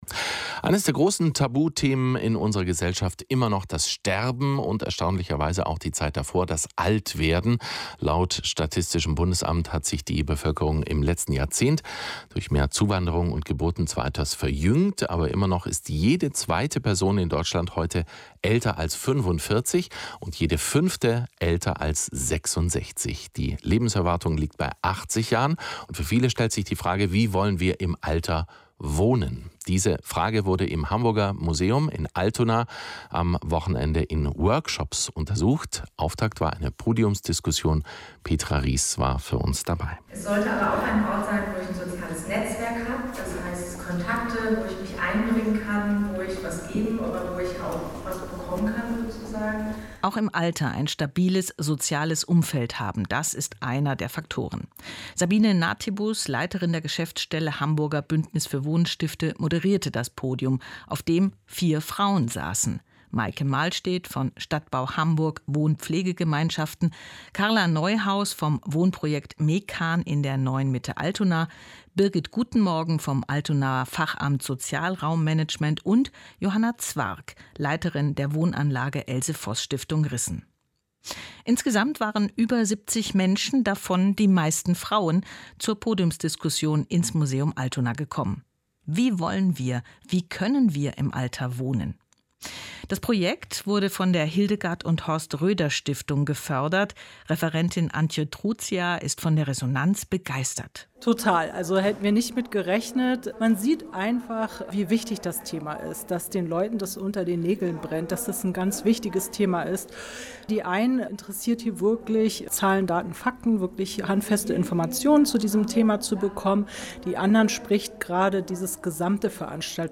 Sehr gefreut hat uns auch, dass der NDR vor Ort war und über unsere Veranstaltung berichtet hat: